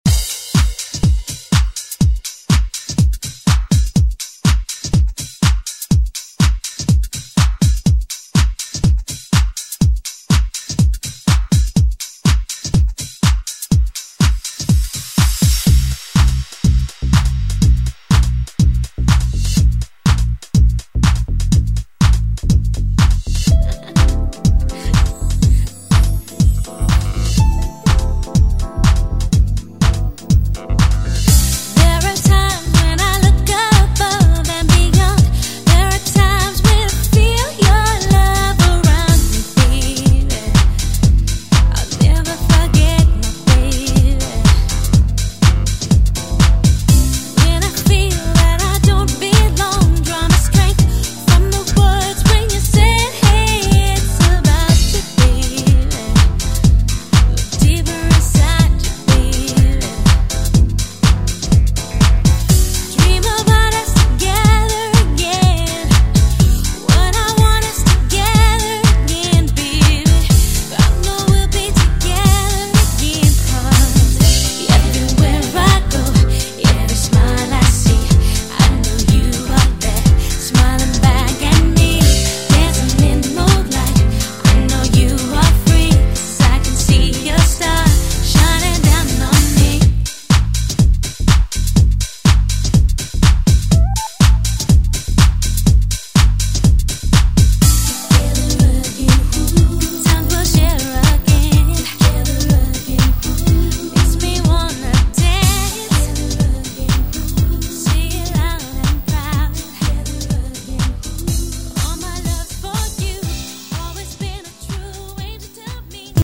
Electronic Dance Euro House Music Extended Club ReWork
Clean BPM: 137 Time